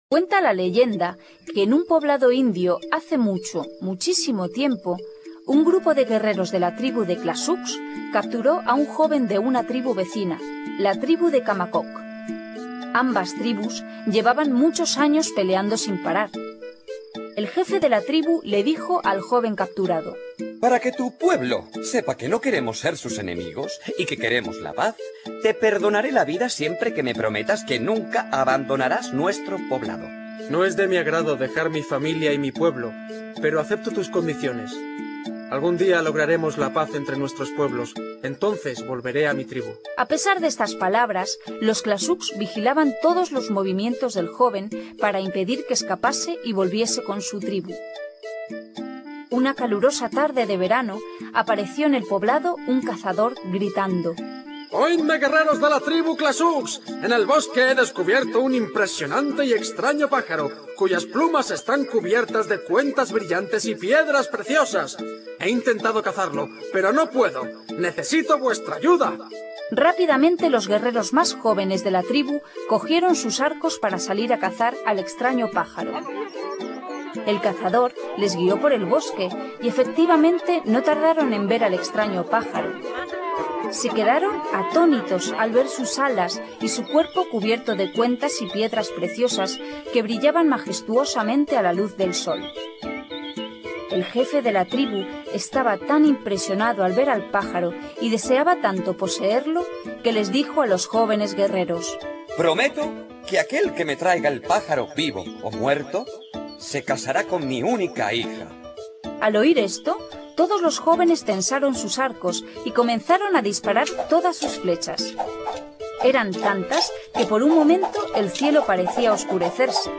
Cuentos infantiles